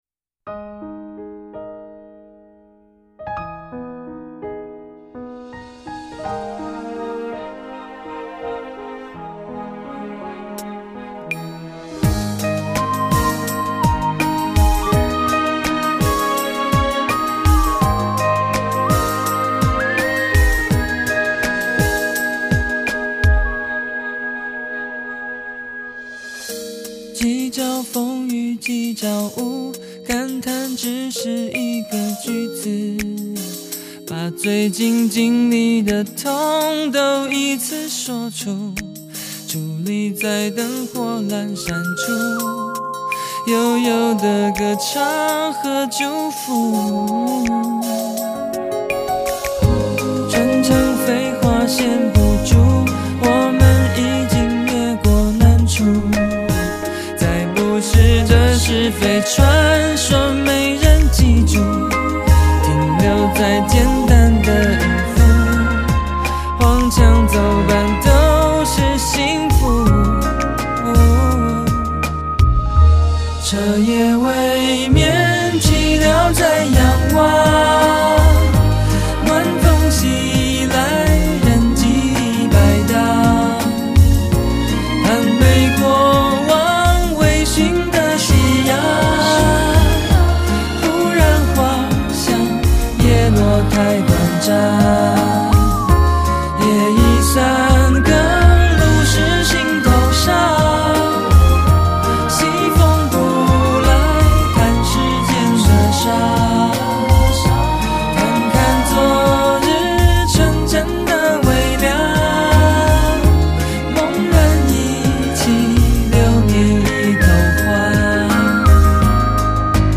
展现了另一种属于古典特殊的韵味